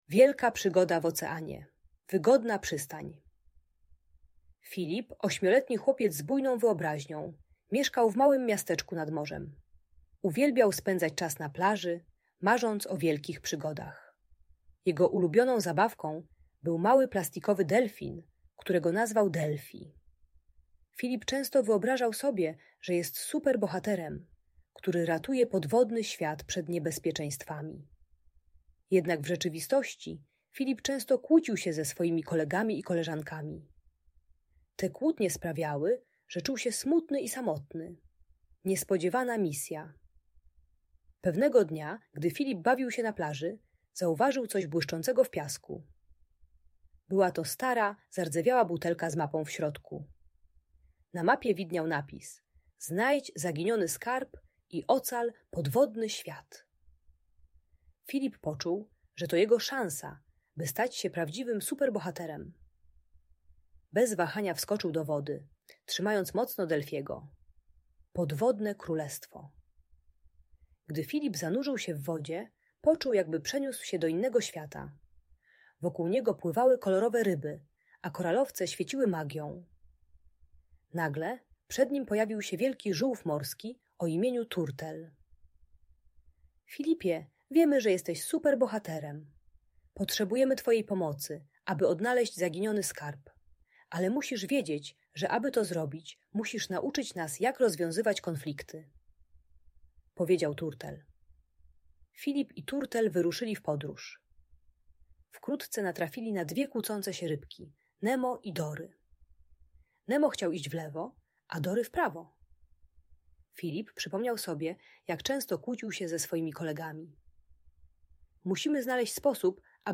Wielka Przygoda w Oceanie - Opowieść o Filipie - Audiobajka